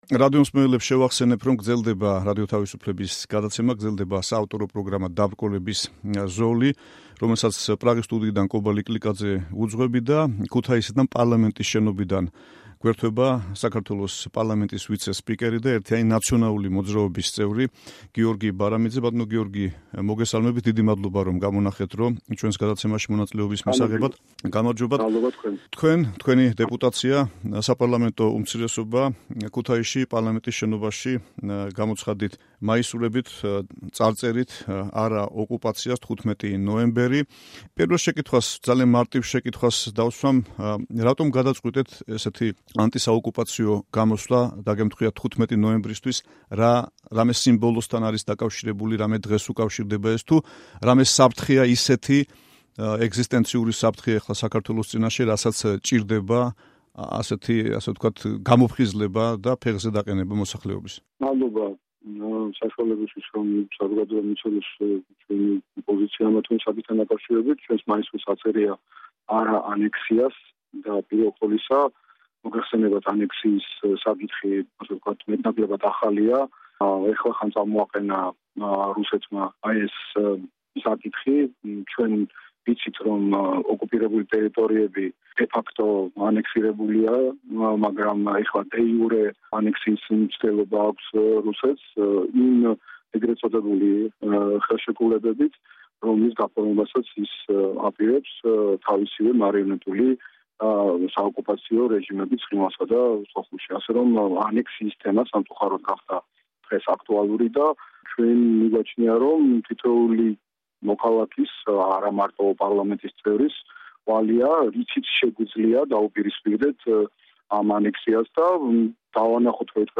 საუბარი გიორგი ბარამიძესთან